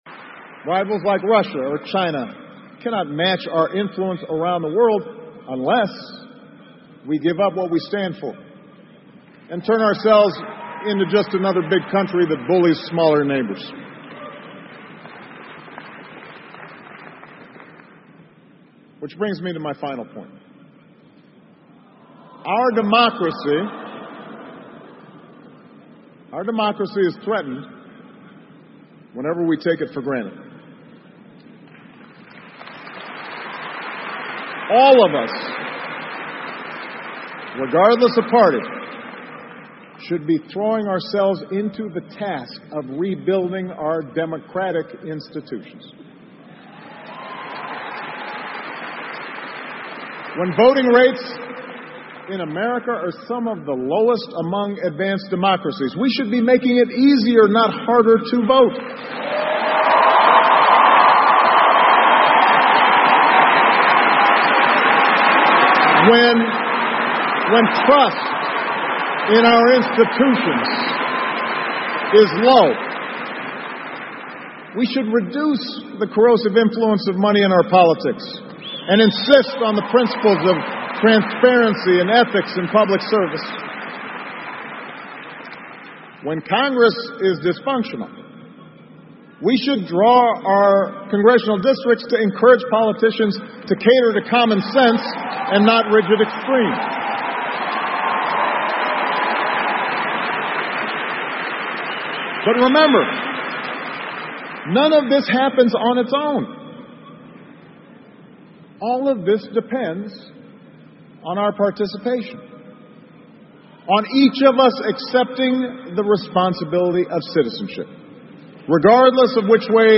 奥巴马每周电视讲话：美国总统奥巴马告别演讲(16) 听力文件下载—在线英语听力室